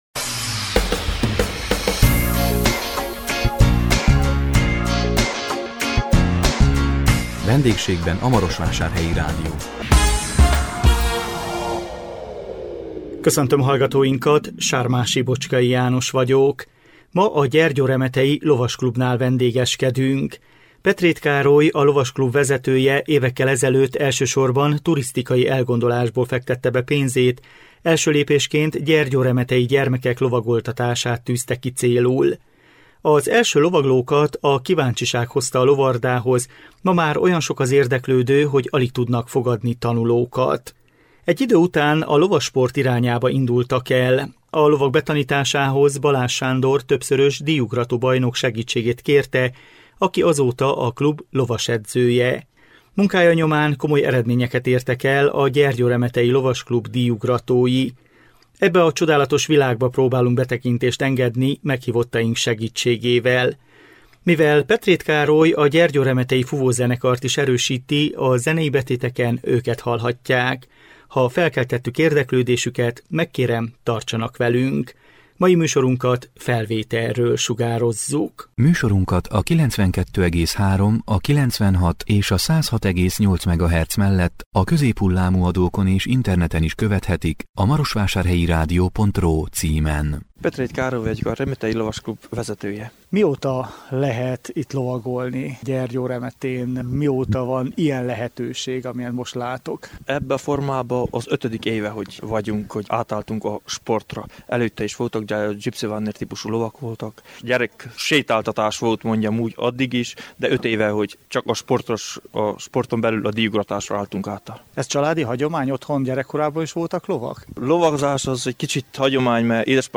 A 2021 április 8-án jelentkező VENDÉGSÉGBEN A MAROSVÁSÁRHELYI RÁDIÓ című műsorunkkal a gyergyóremetei Lovasklubnál vendégeskedtünk.